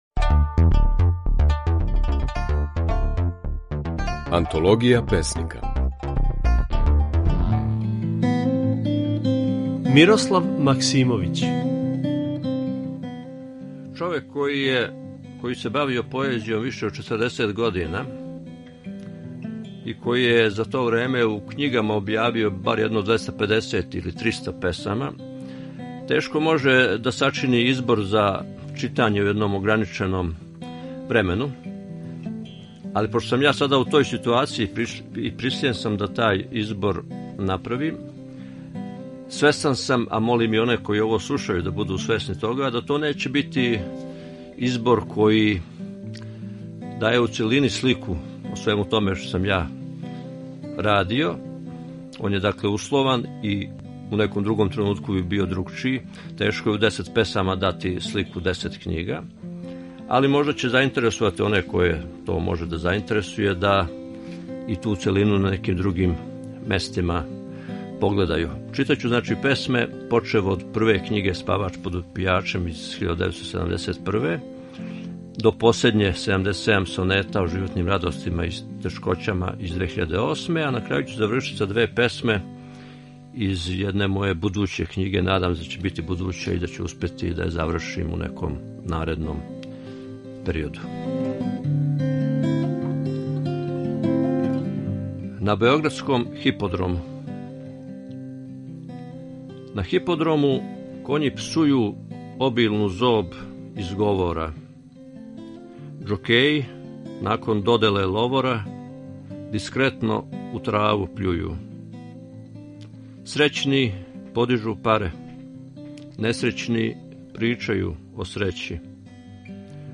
Mожете чути како своје стихове говори песник Мирослав Максимовић.